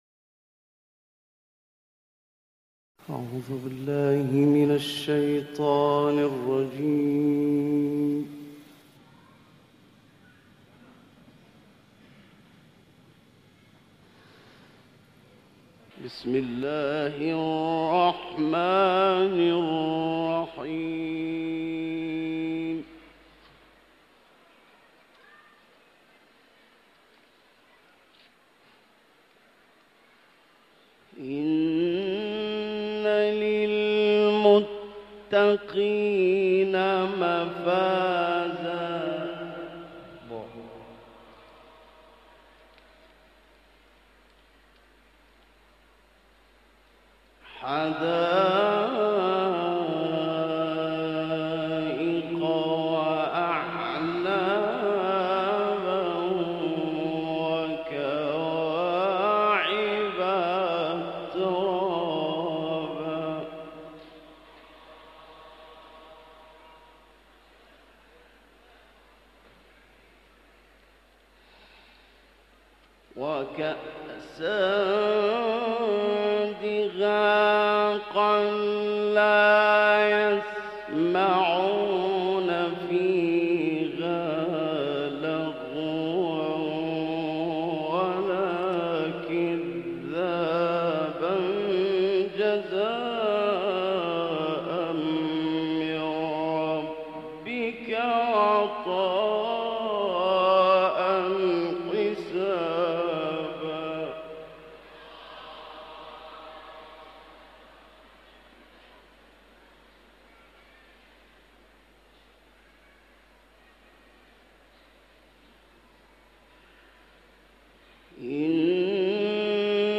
گروه فعالیت‌های قرآنی: قطعه‌ای از تلاوت محمد اللیثی از آیات ۳۱ تا ۴۰ سوره نبأ، آیات ۲۶ تا ۴۶ سوره نازعات و سوره انفطار ارائه می‌شود.